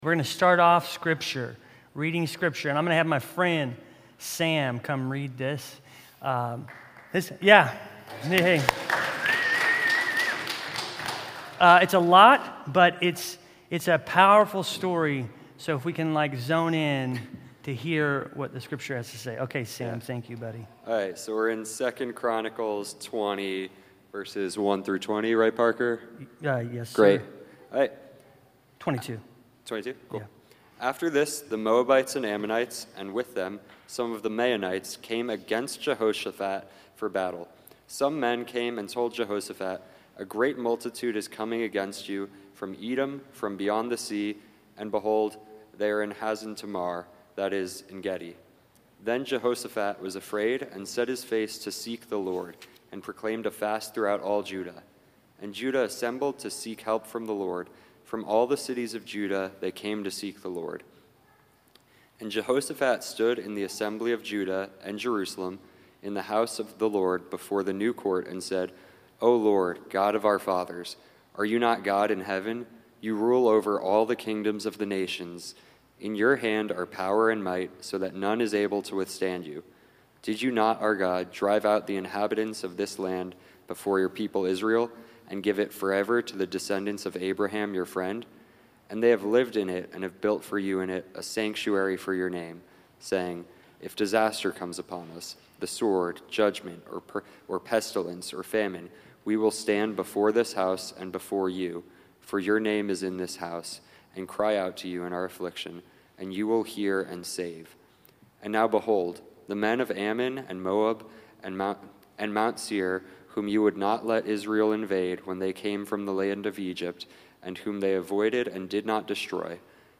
JUNE-29-Full-Sermon.mp3